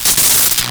boltexplode4.wav